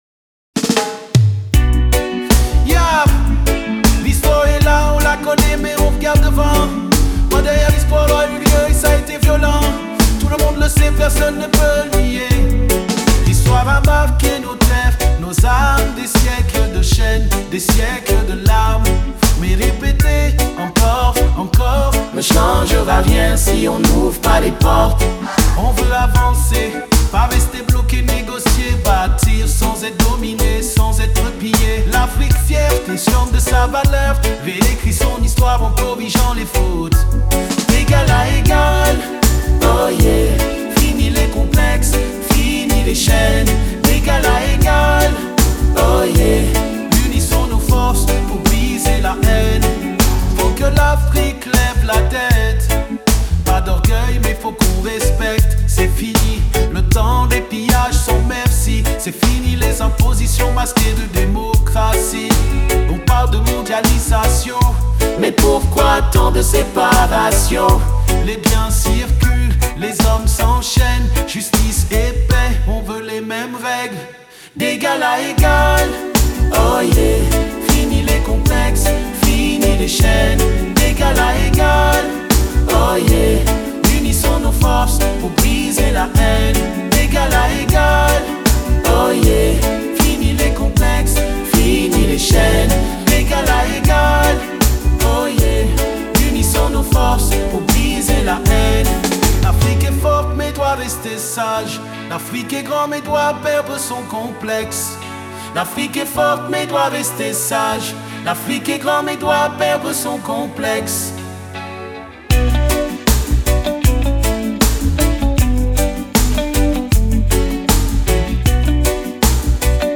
🎶 Reggae / Rap conscient / Afro‑fusion